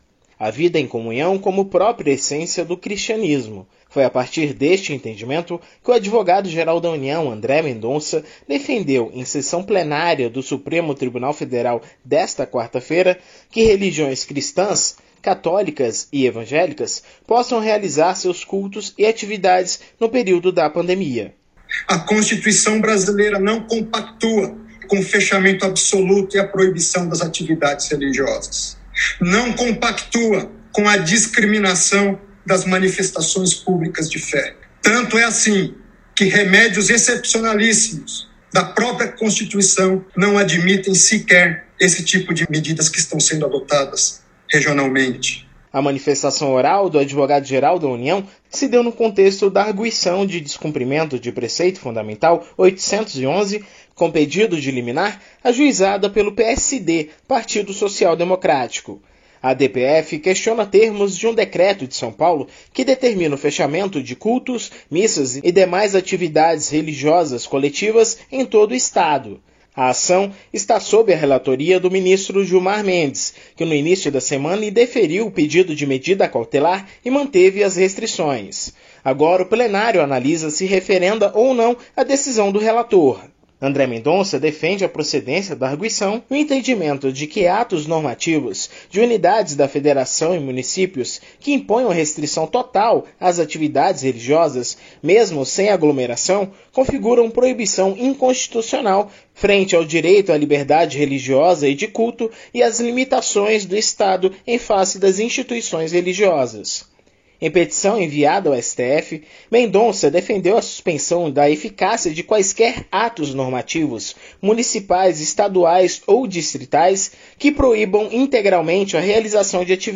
Em sua primeira sustentação oral na Corte depois de reconduzido à AGU, o Advogado-Geral defendeu a realização de missas, cultos e outras atividades religiosas presenciais para enfrentamento da pandemia